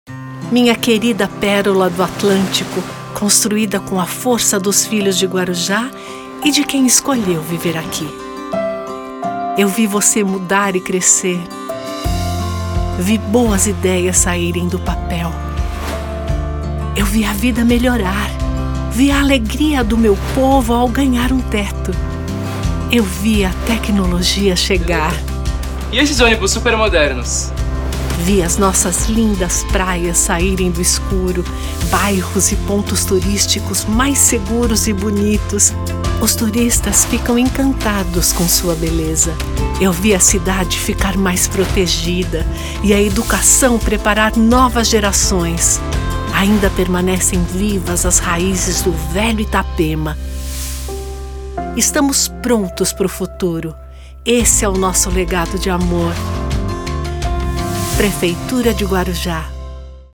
Commercieel, Natuurlijk, Vertrouwd, Zacht, Zakelijk
Explainer